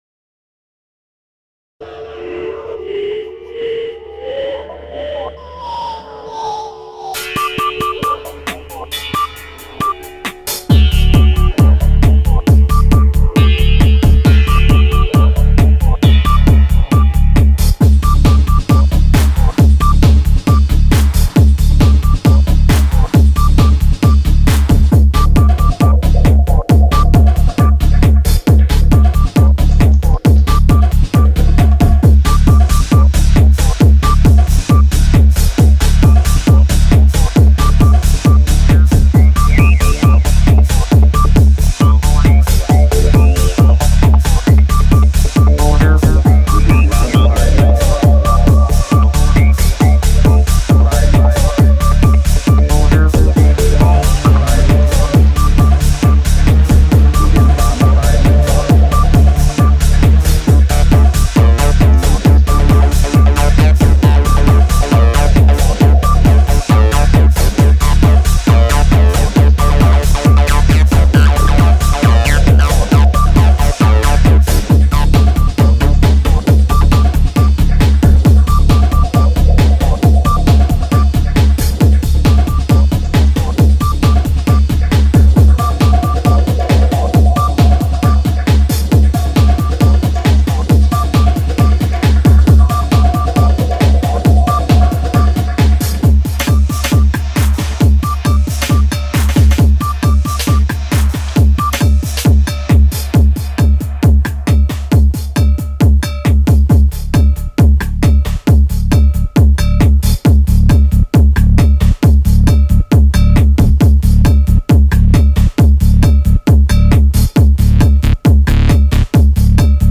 "we begin bombing in 5 minutes" has been used zillions of times and now i used it again :)
the cowbell part was caused by (simon the cat) jumpimng down. and walking on the monome and maschine
quite a spacious soundscape!
Main drums were NI maschine, with
sampled loops in Monome + Molar
Bass and a couple of synth sounds were Virus TI
Synth effect sounds were 2 instances of paradox and a DSI Mopho
303 sound was Futureretro revolution.
any reverb or delays were just ones built in to the respective instruments except the revolution and mopho went through a KP3
Excellento - infectious, especially the 303 lines.